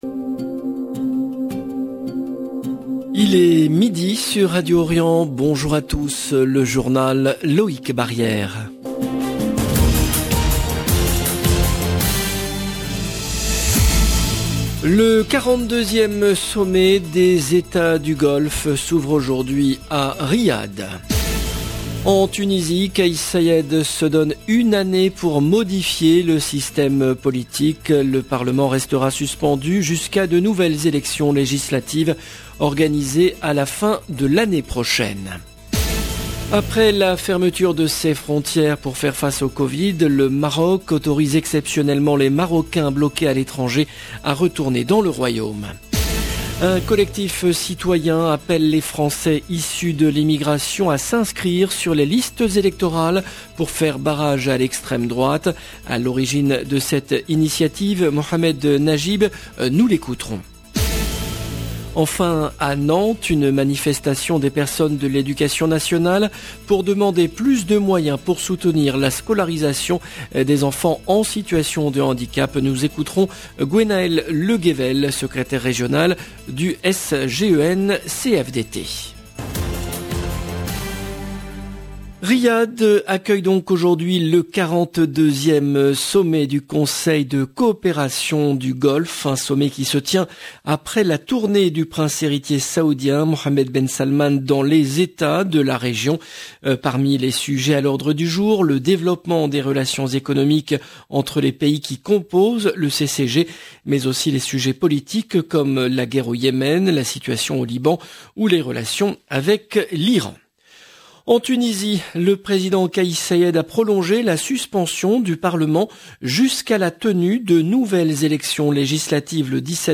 LE JOURNAL DE MIDI EN LANGUE FRANCAISE DU 14/12/21